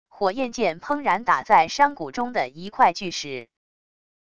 火焰箭砰然打在山谷中的一块巨石wav音频